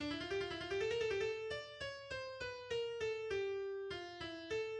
Chromatic run from Chopin's Prelude in C Minor, mm.5-6.[1] Play